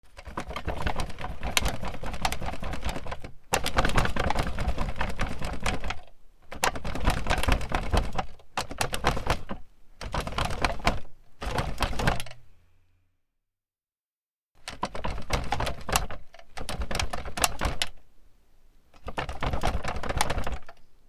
椅子をゆする 『ギシギシ』
/ J｜フォーリー(布ずれ・動作) / J-22 ｜椅子